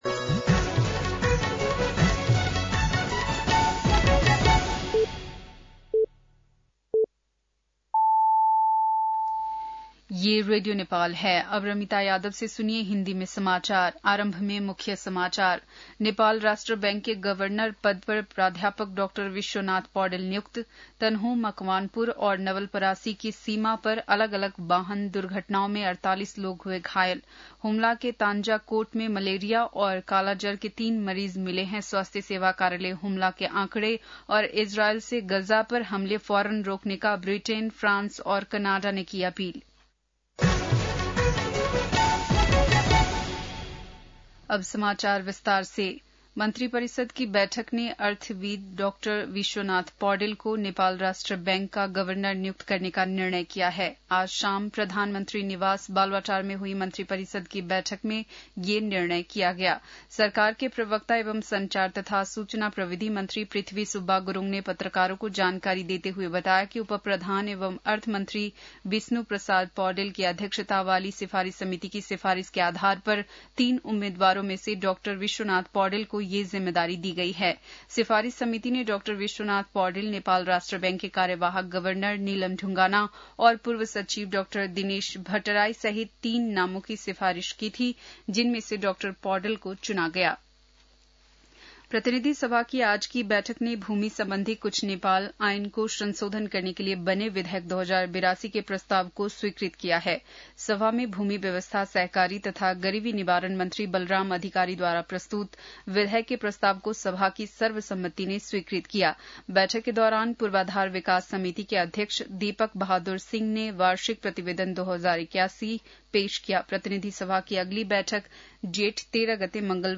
बेलुकी १० बजेको हिन्दी समाचार : ६ जेठ , २०८२
10-pm-hindi-news-2-06.mp3